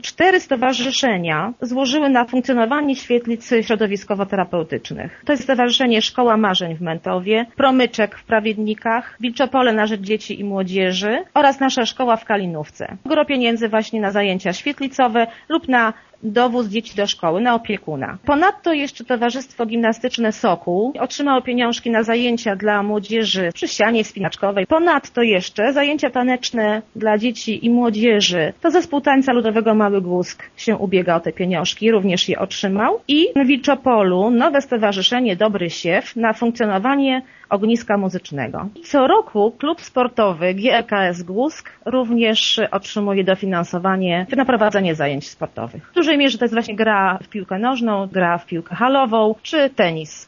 „W sumie środki otrzymało 8 organizacji” - informuje zastępca wójta Gminy Głusk Urszula Paździor: